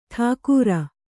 ♪ ṭhāku